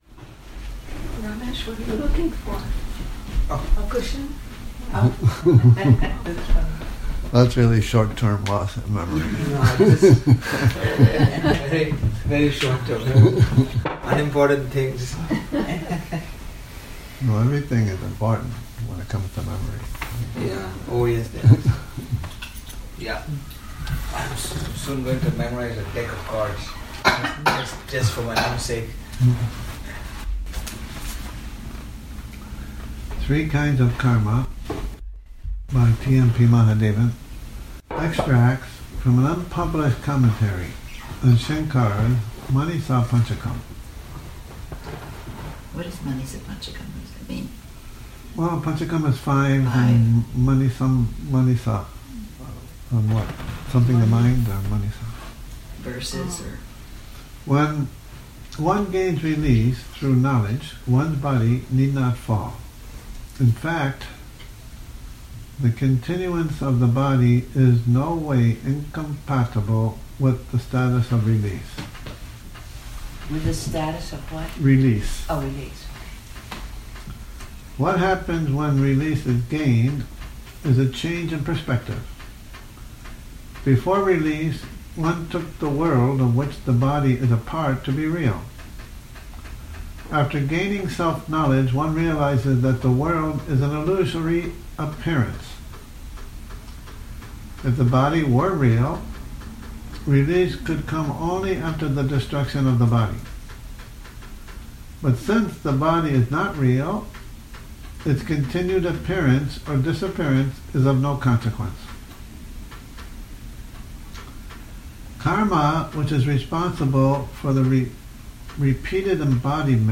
Morning Reading, 28 Nov 2019